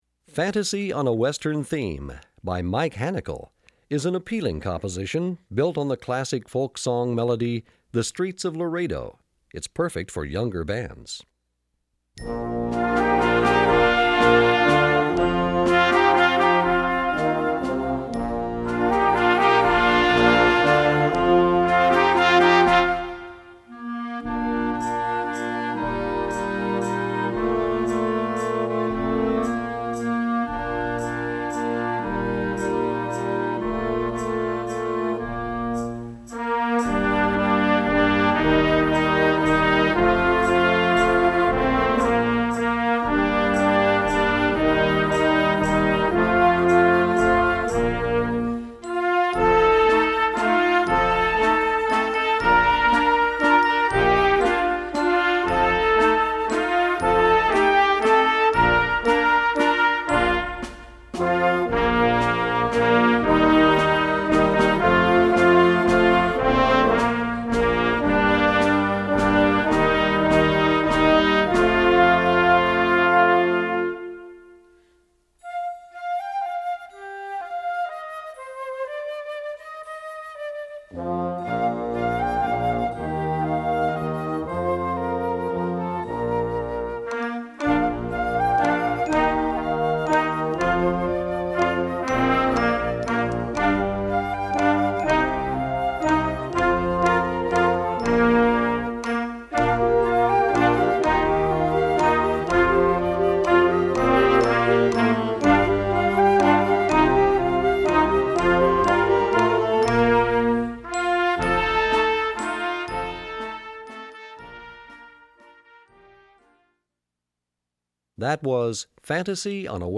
Besetzung: Blasorchester
lebhaften, im Westernstil gehaltenen Nummer